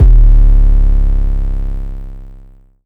DIST2M808.wav